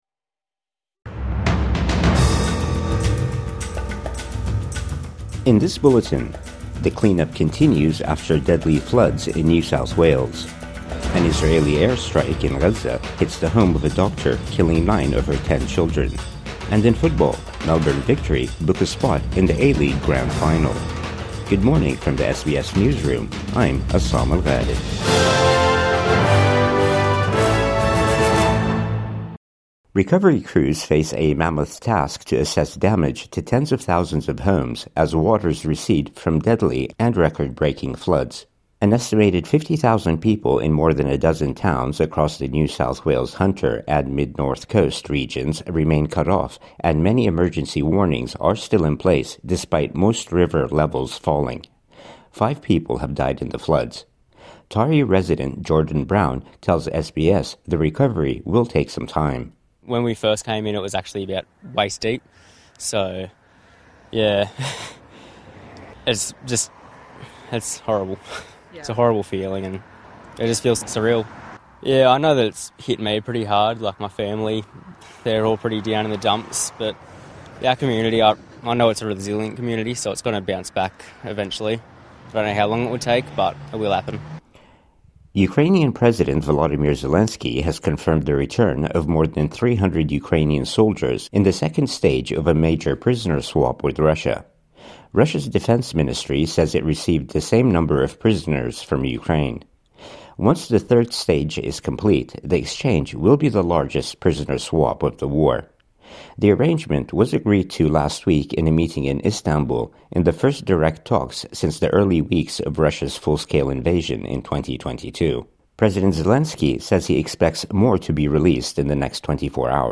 Morning News Bulletin 25 May 2025